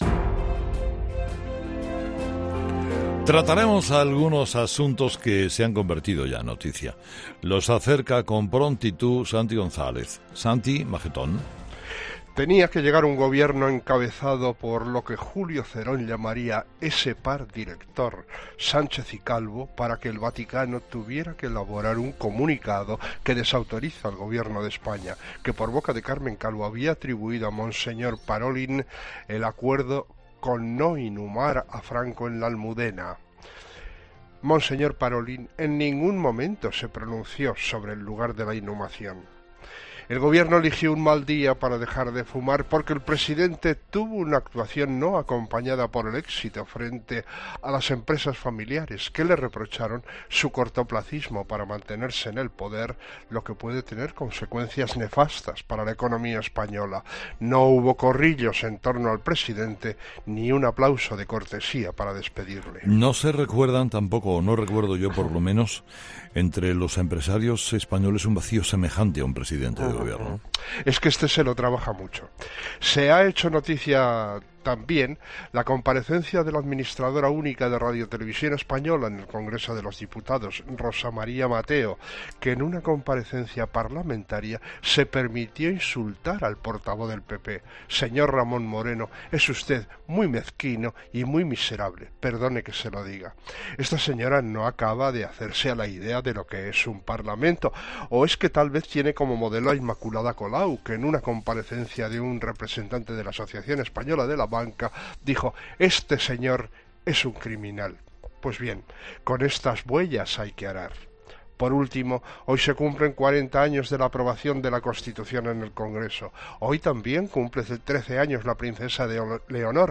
El comentario de Santiago González